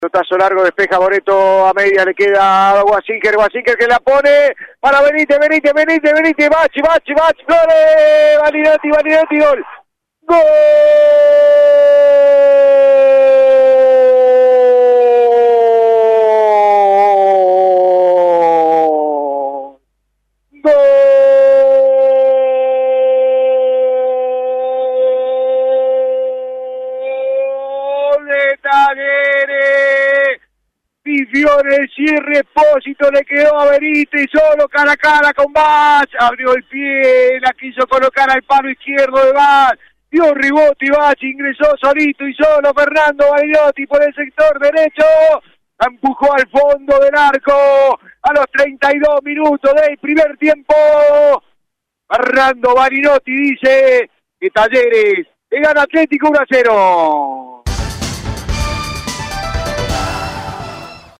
Fue transmisión de la radio
GOL: